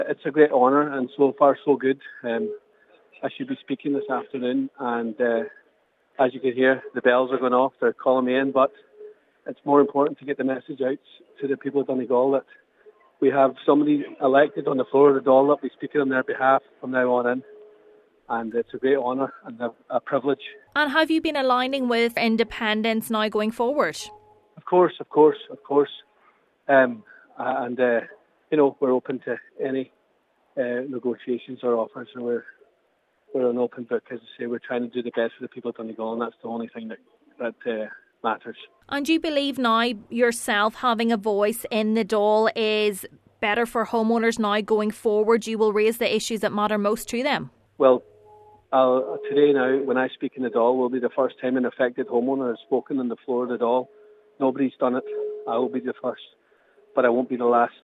Deputy Ward says he will be taking to the floor of the Dail later today: